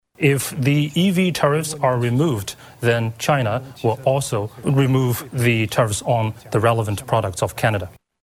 The Chinese ambassador to Canada, Wang Di (Dee), spoke through a translator to deliver Beijing’s message.